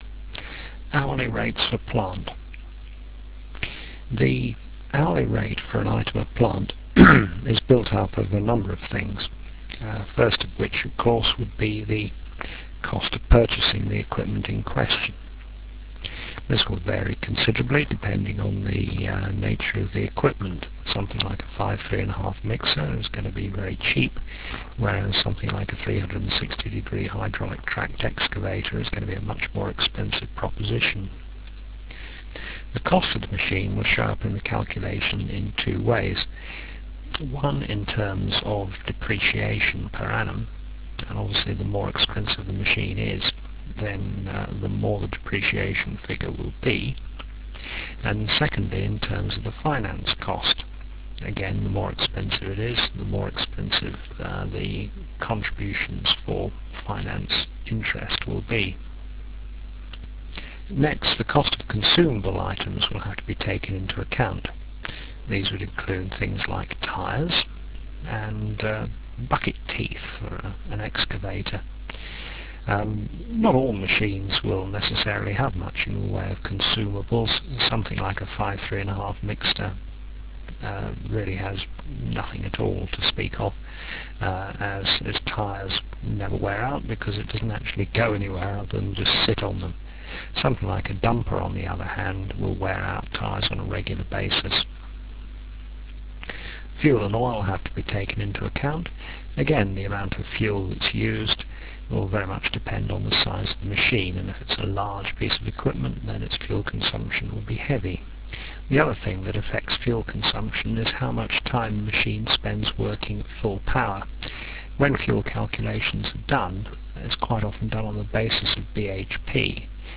The sound quality is adequate but low fi.
Enjoy your lectures.........